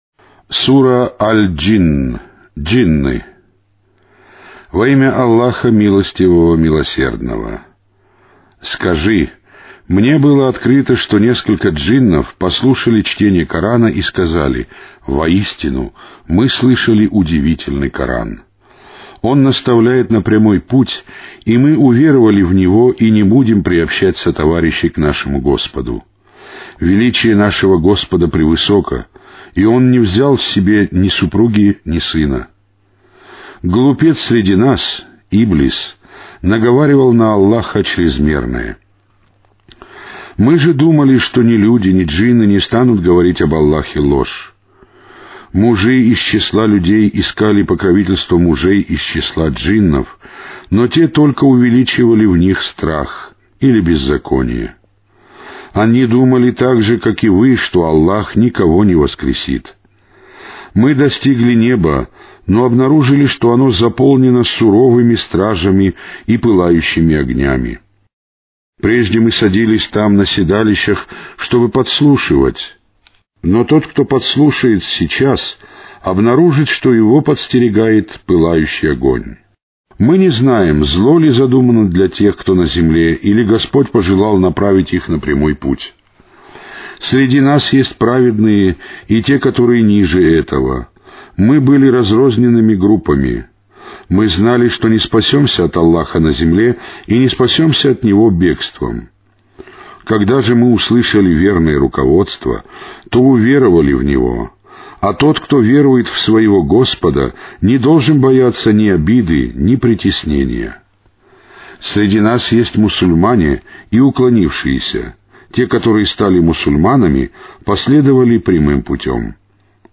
Каналы 1 (Mono).
Аудиокнига: Священный Коран